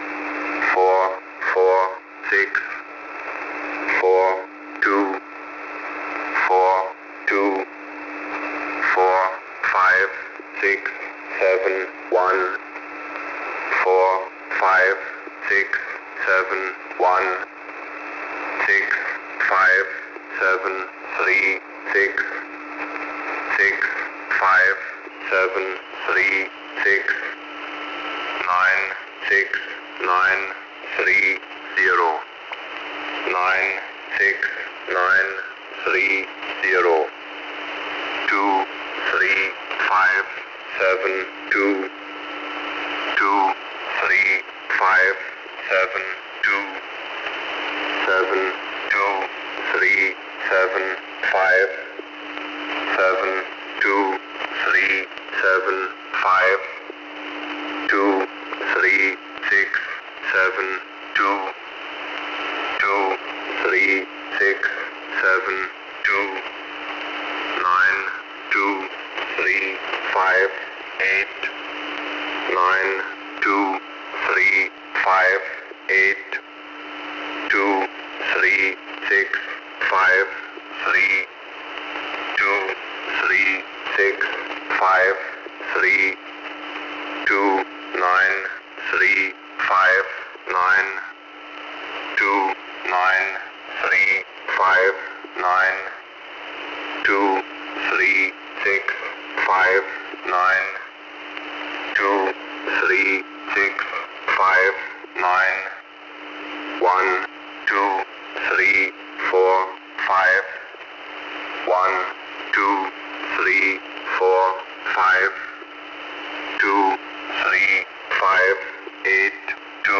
mentioned ID once and preceded to message immediately due to automated voice issues